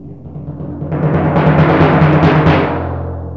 TIMP 4.WAV